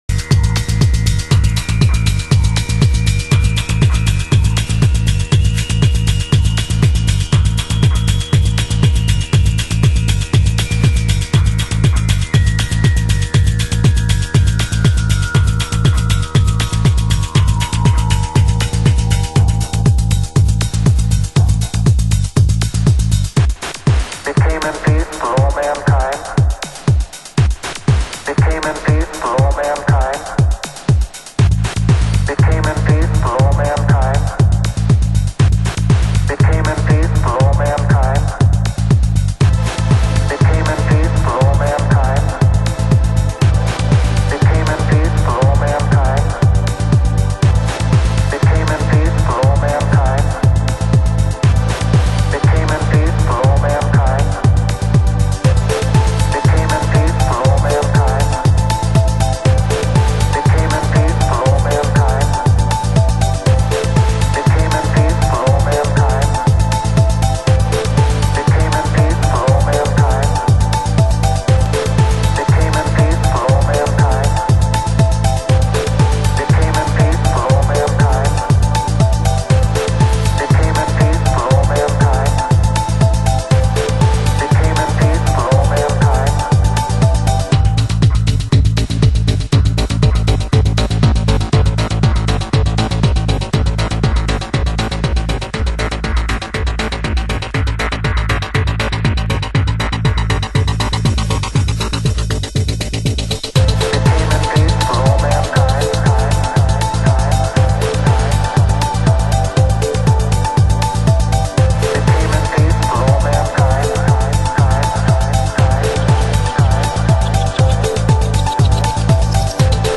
盤質：少しチリパチノイズ有/軽いスレ傷有/重量盤